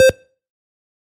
На этой странице собраны звуки, связанные с покупками и оплатой: работа кассового аппарата, сигналы терминалов, уведомления об успешной транзакции.
Звук сканирования товара через штрих код